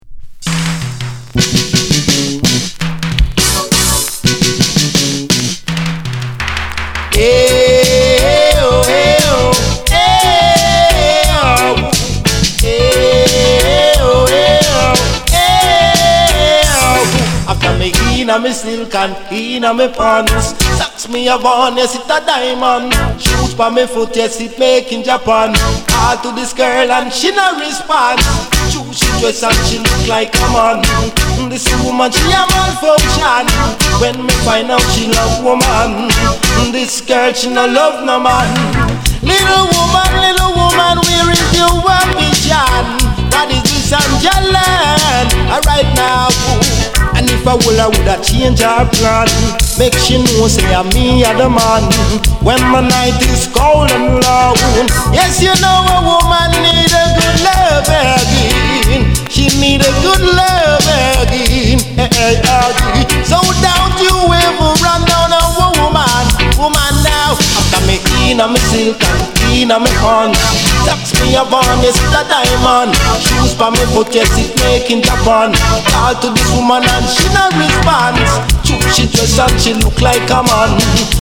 Genre: Reggae/Dance hall